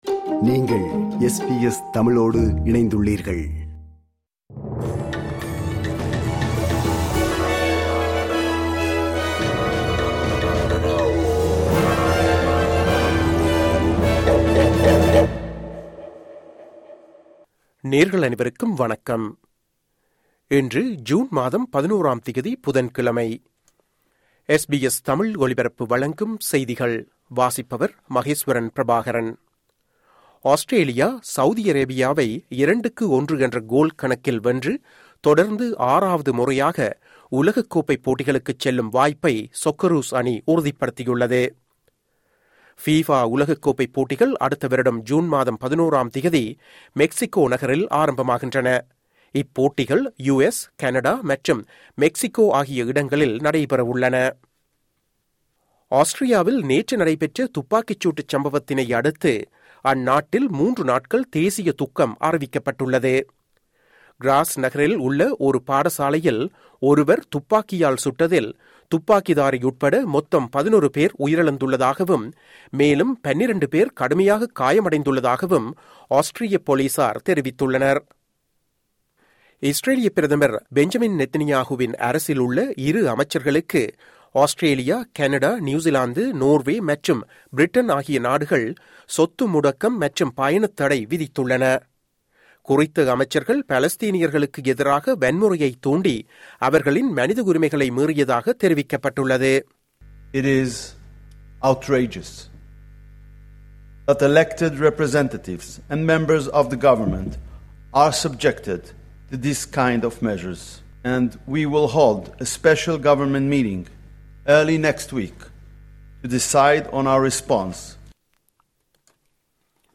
SBS தமிழ் ஒலிபரப்பின் இன்றைய (புதன்கிழமை 11/06/2025) செய்திகள்.